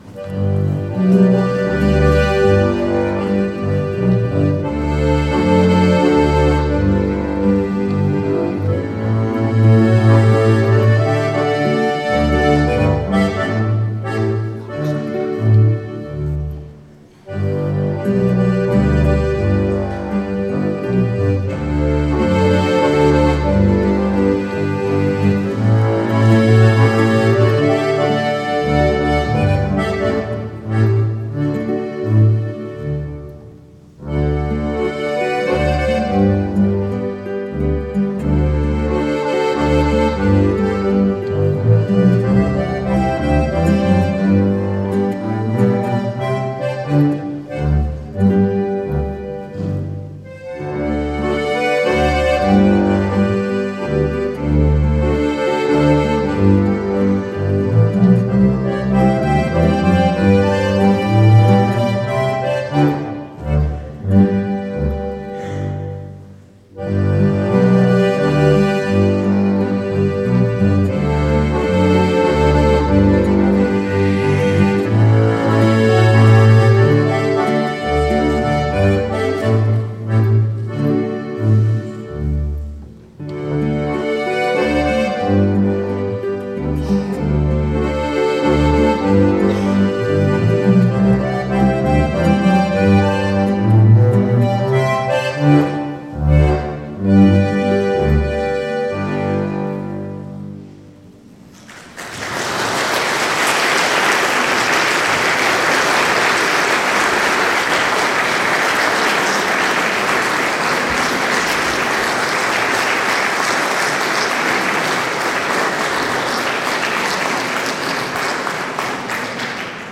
Ziehharmonika, Gitarre und Kontrabass ein sehr angenehmes und familiäres Flair
Die Musikstücke aus dem Gottesdienst (einzelne Titel mittels Mausklick abspielen) Einzug Eröffnung Kyrie Antiphon Gabenbereitung Sanctus Vater Unser Kommunion Danklied Auszug